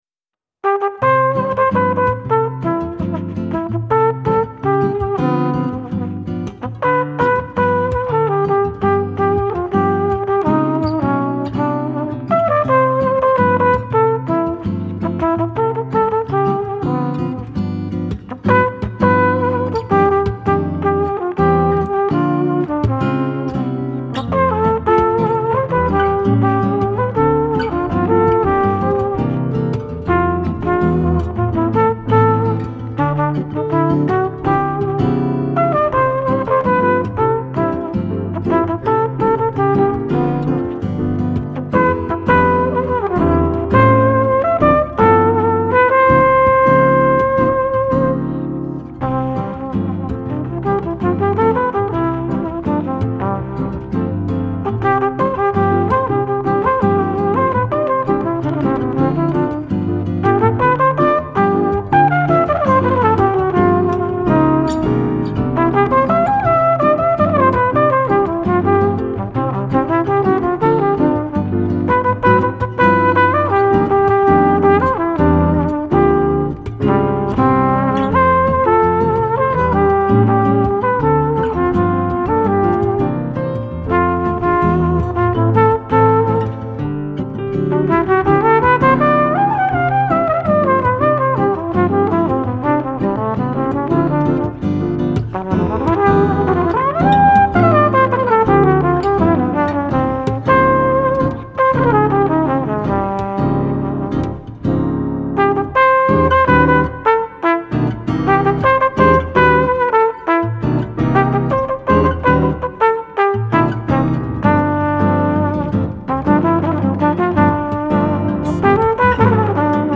Genre : Jazz contemporain